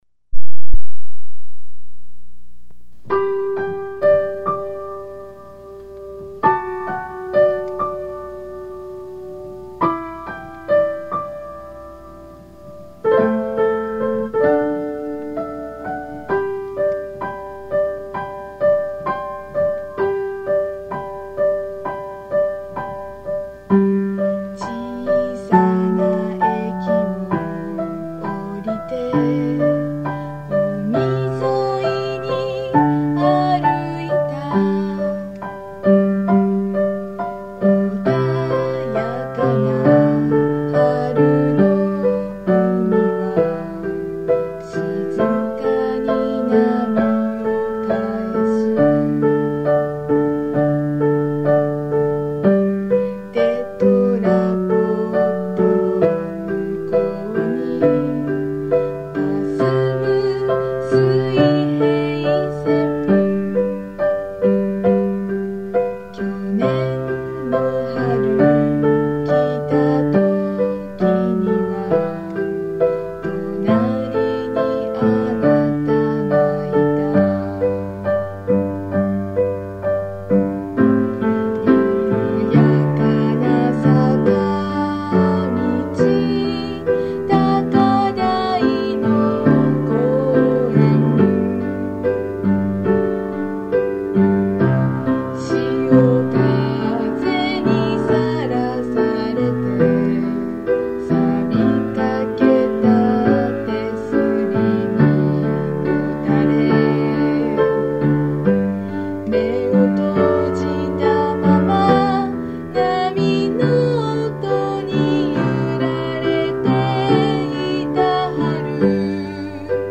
（Original Key : G major, by piano）